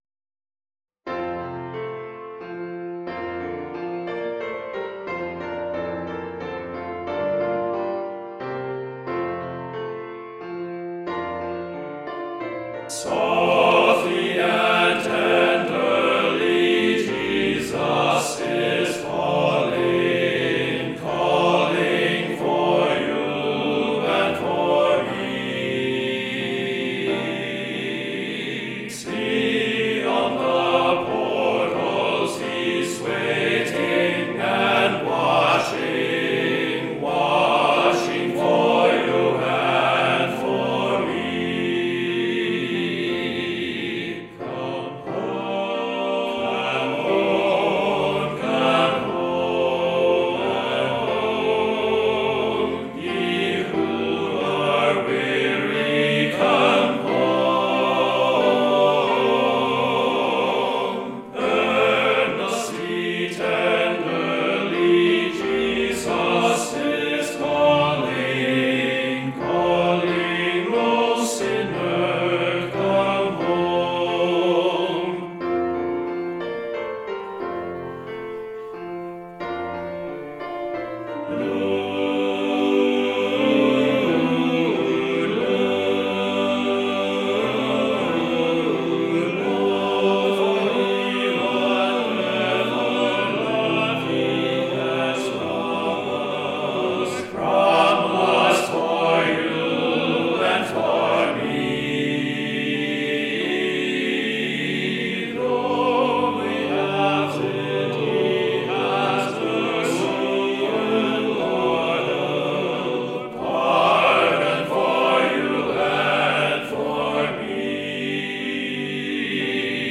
for TTBB choir with piano accompaniment